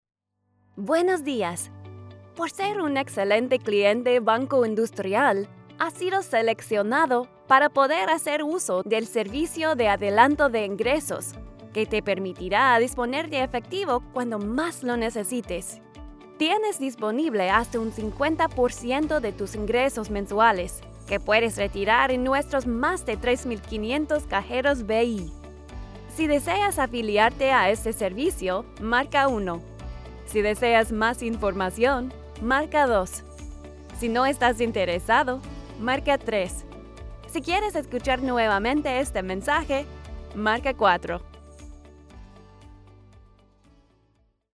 Female
Yng Adult (18-29), Adult (30-50)
Warm and bubbly, your most trustworthy neighbor and bestie!
Spanish Demo
Words that describe my voice are Trustworthy, Warm, Friendly.